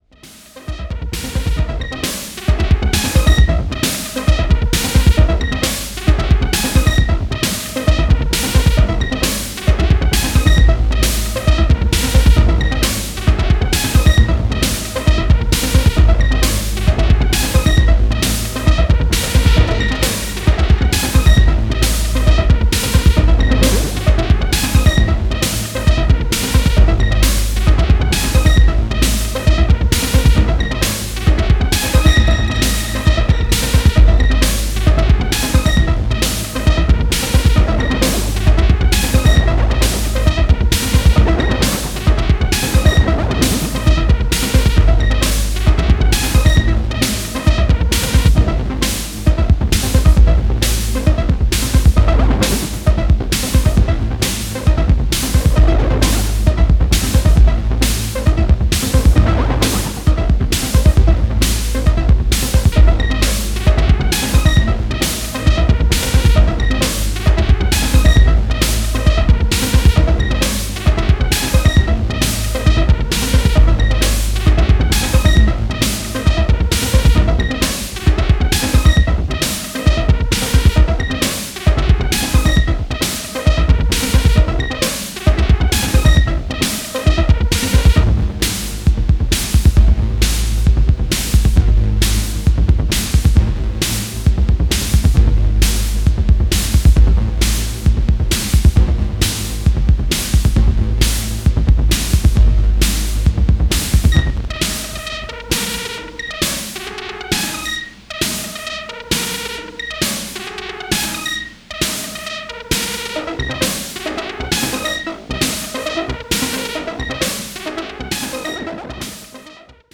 dedicated to a more abstract and experimental electro sound